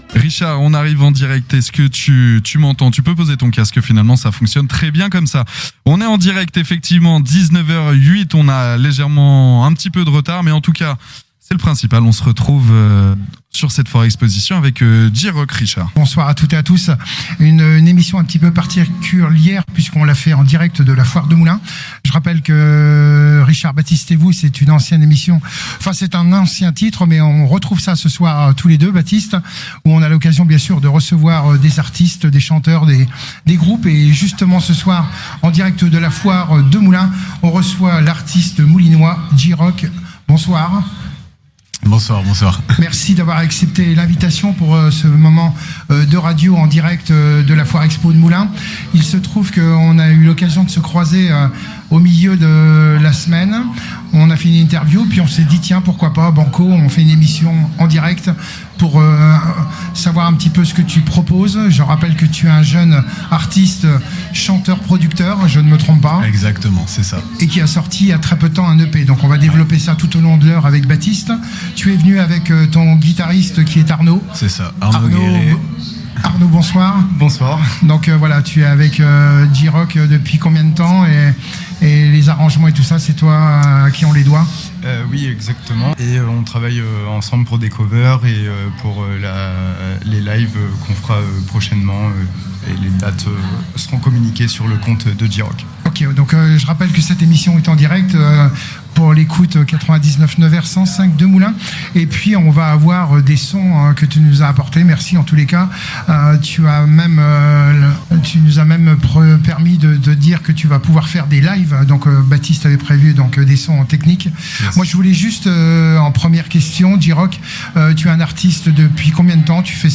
Foire de Moulins 2025